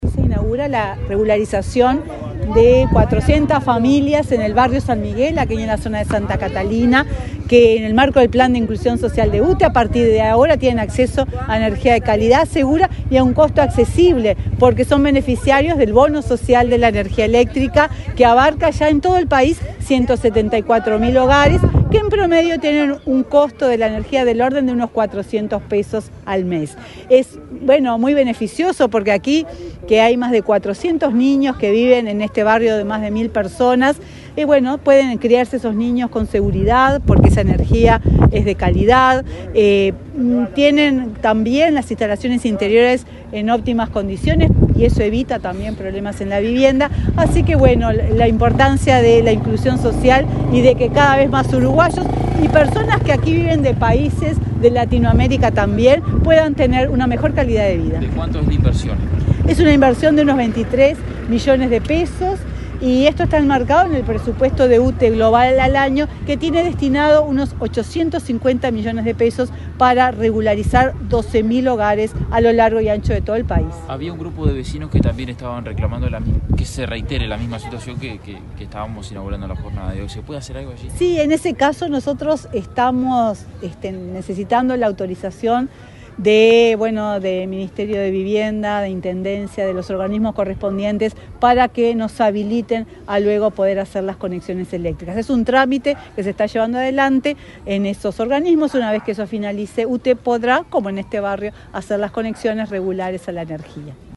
Declaraciones de la presidenta de UTE, Silvia Emaldi
Declaraciones de la presidenta de UTE, Silvia Emaldi 28/06/2024 Compartir Facebook X Copiar enlace WhatsApp LinkedIn Este viernes 28, la presidenta de la UTE, Silvia Emaldi, dialogó con la prensa, luego de participar en la inauguración de obras del programa Inclusión Social de la empresa pública proveedora de energía en el barrio San Miguel, en Montevideo.